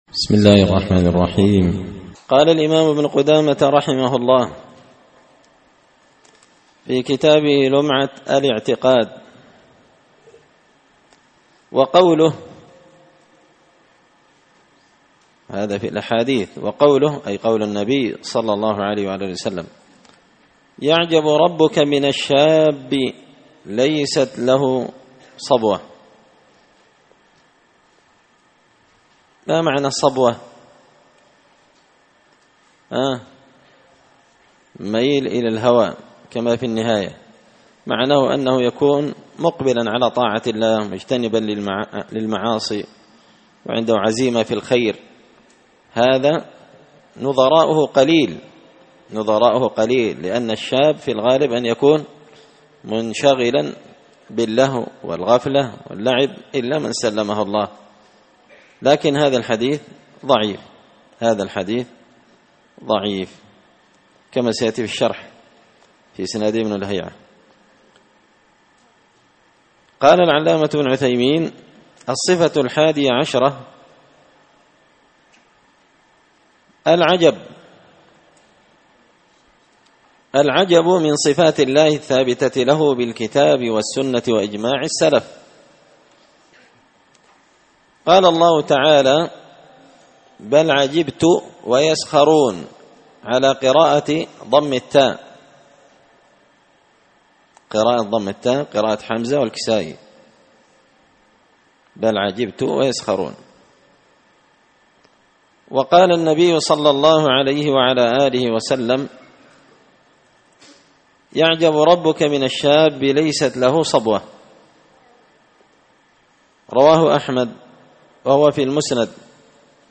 شرح لمعة الاعتقاد ـ الدرس 18
دار الحديث بمسجد الفرقان ـ قشن ـ المهرة ـ اليمن